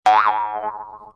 Звуки восточной музыки
Одиночный звон